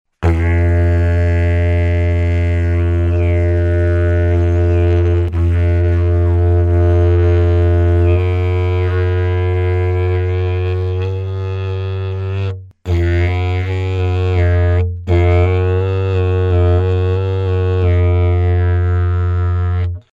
Диджебокс Тональность: F
Диджебокс является спиралевидной версией диджериду, что обуславливает его компактные размеры, по сравнению со стандартными моделями. Данная модель имеет два экспериментальных игровых отверстия, позволяющих изменять звучание по пол-тона.